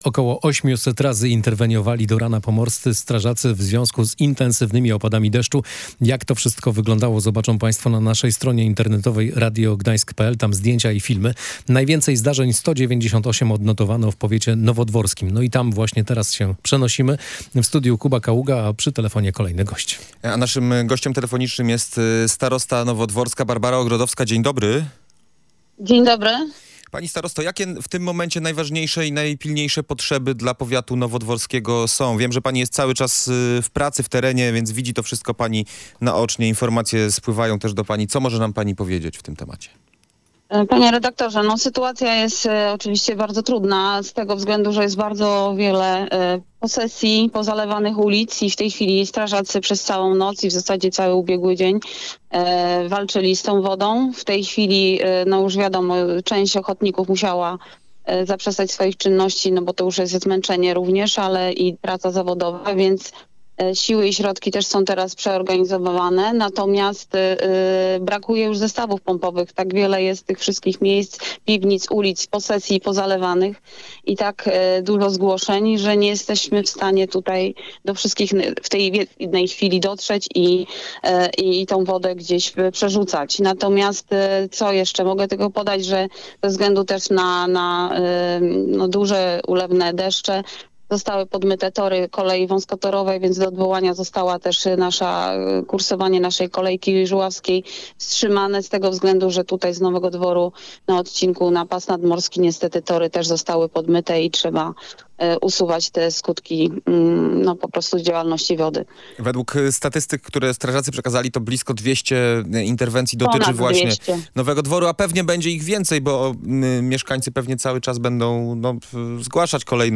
Sytuacja po ulewach jest bardzo trudna – mówiła na antenie Radia Gdańsk Barbara Ogrodowska, starosta nowodworski. Ratownicy interweniowali tam ponad 200 razy.